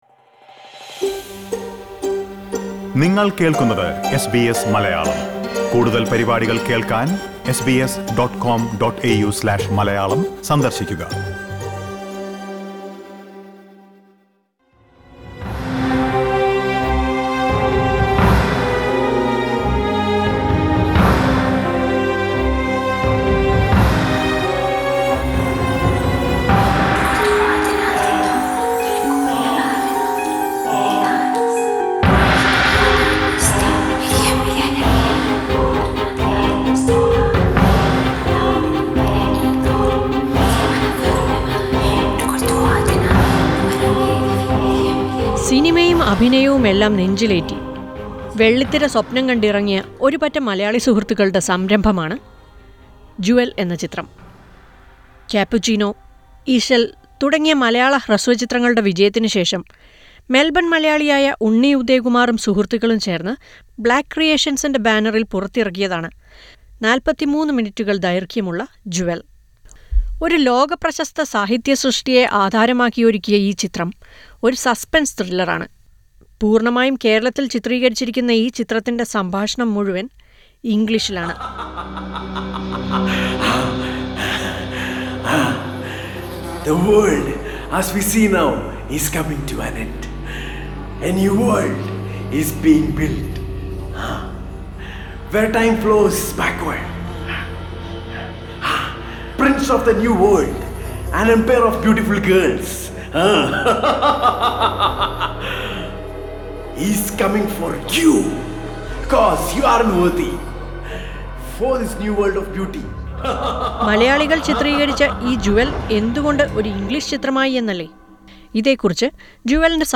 Listen to a report on 'Jewel' a short film by a group of Malayalee friends.